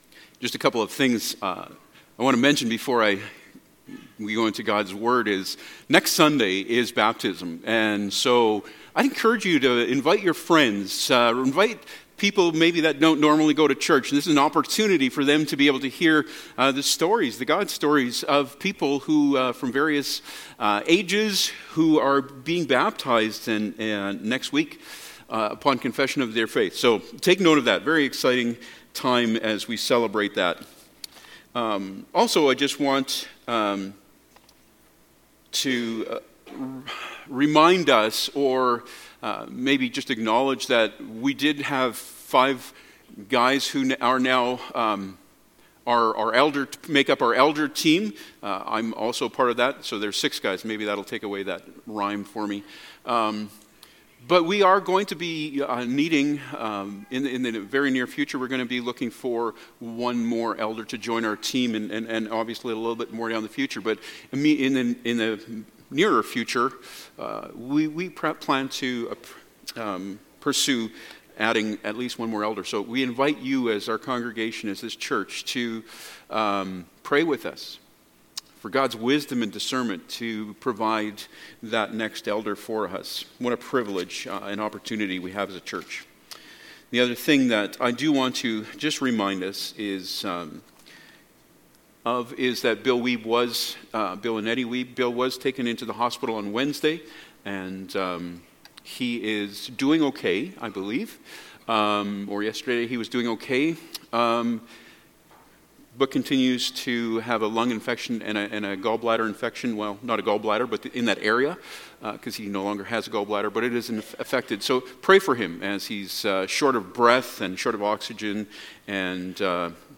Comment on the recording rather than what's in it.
Passage: 1 Peter 5:6-14 Service Type: Sunday Morning